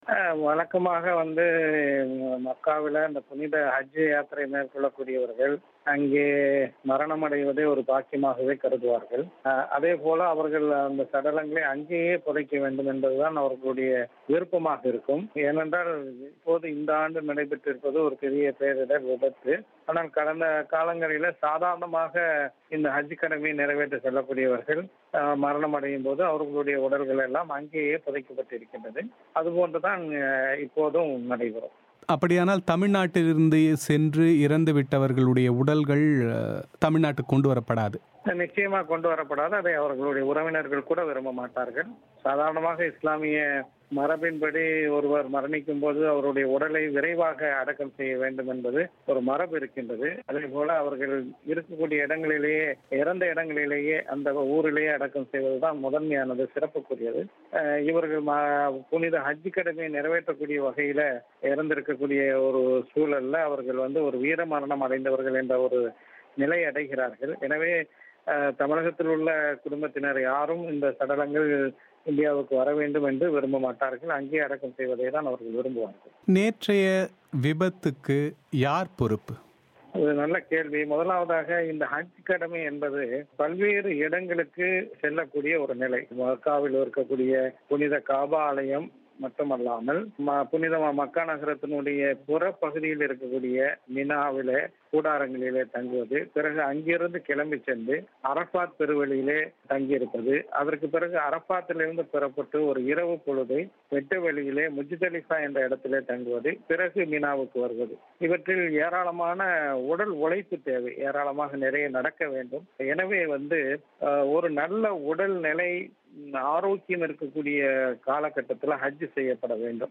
மக்காவில் நடந்த விபத்து குறித்தும், அது தொடர்பான விமர்சனங்கள் குறித்தும் ஜவாஹிருல்லாஹ் பிபிசி தமிழோசைக்கு அளித்த விரிவான செவ்வியை நேயர்கள் இங்கே கேட்கலாம்.